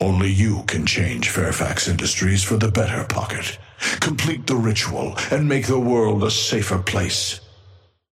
Patron_male_ally_synth_start_02.mp3